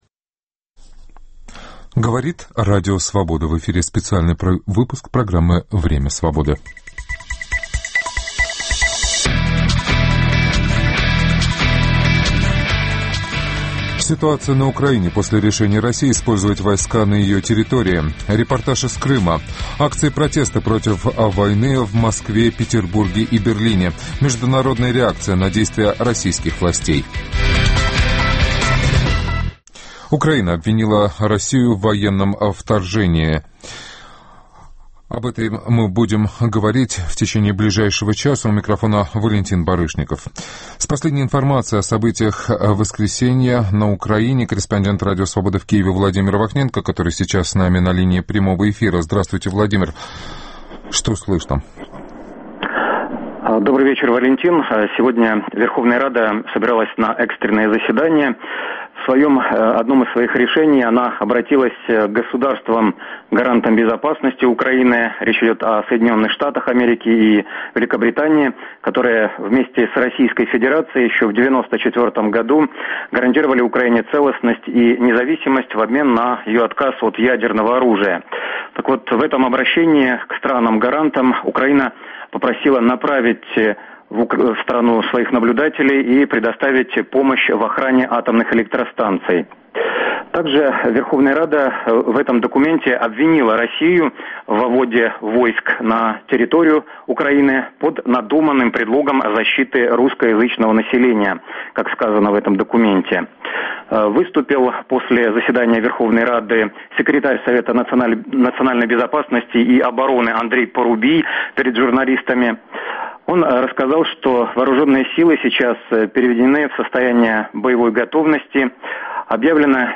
Ситуация на Украине после решения России использовать войска на ее территории. Репортаж из Крыма.